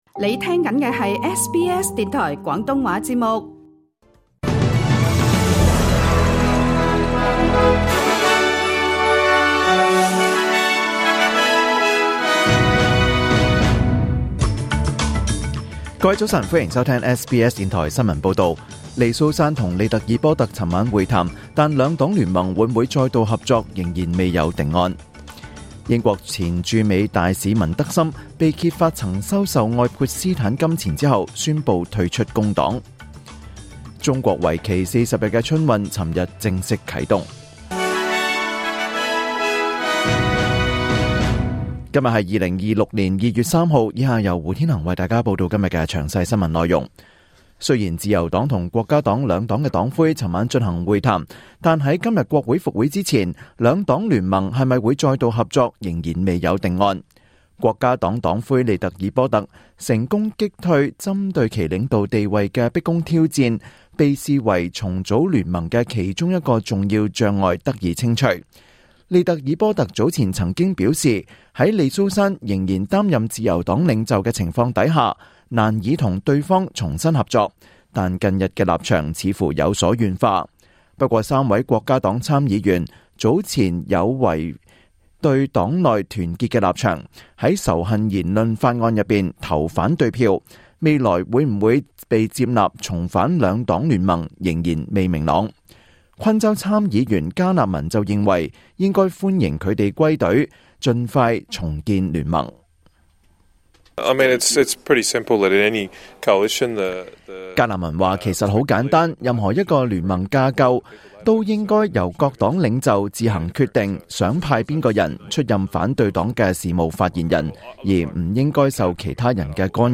2026年2月3日SBS廣東話節目九點半新聞報道。